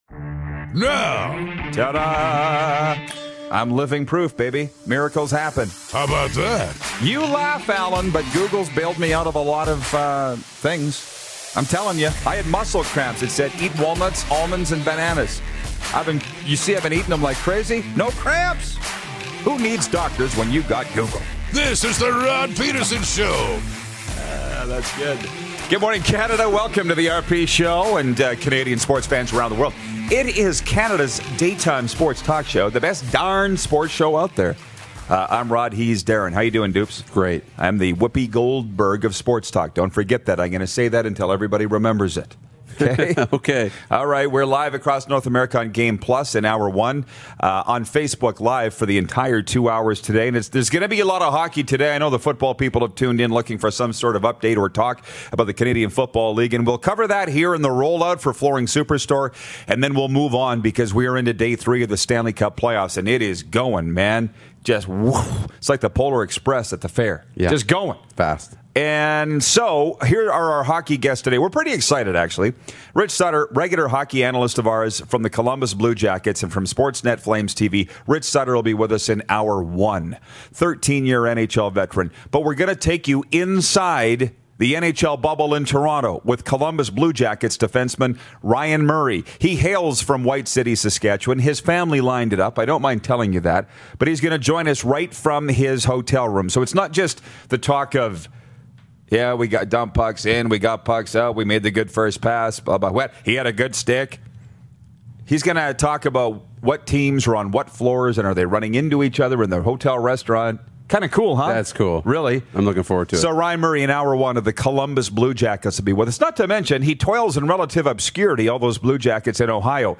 RPShow Hockey Analyst and NHL Scout/Alumni Rich Sutter joins us to talk about the NHL Playoffs in Hour 1! We have a very special interview with Sasky Boy and Columbus Blue Jackets D-Man Ryan Murray also in Hour 1!